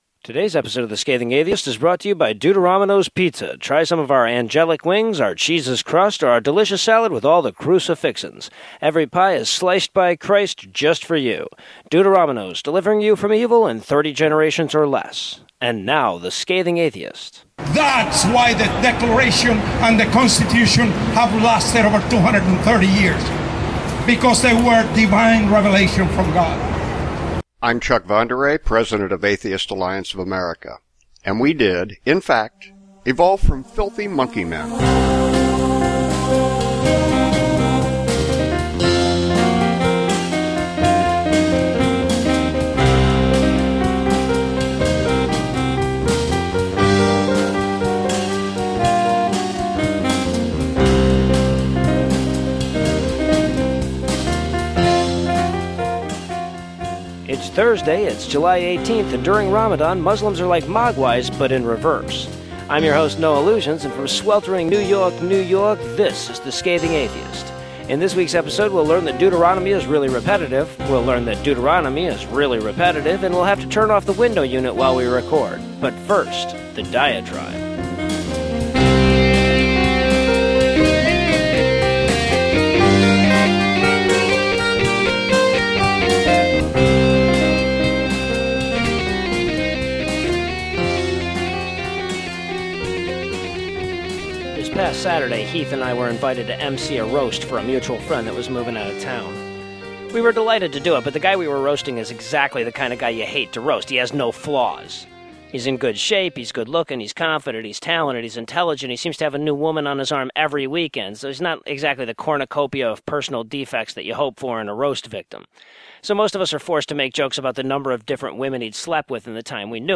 In this week's episode we'll learn that Deuteronomy is really repetitive, we'll learn that Deuteronomy is really repetitive and we'll suffer through a heat wave with the window unit off for the sake of sound quality.